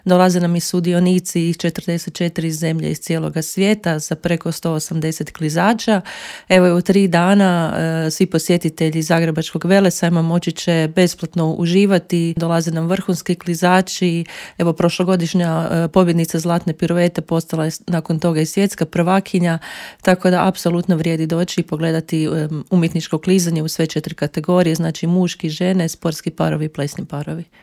u studiju Media Servisa